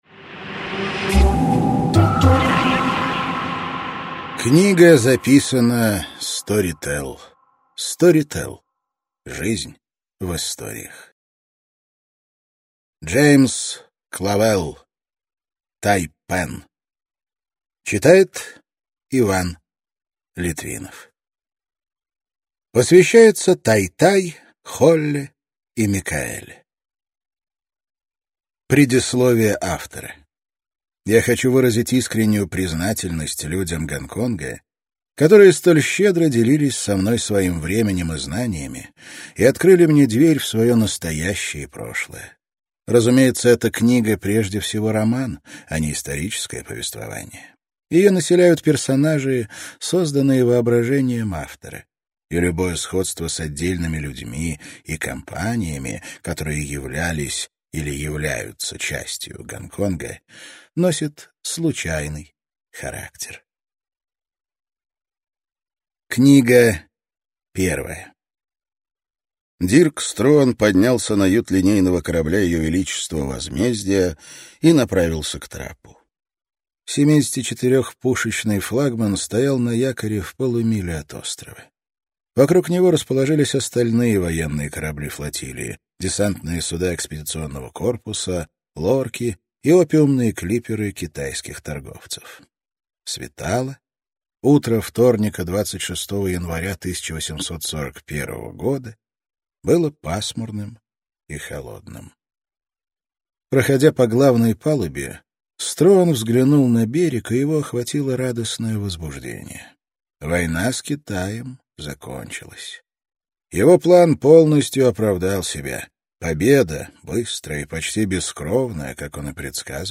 Аудиокнига Тайпан | Библиотека аудиокниг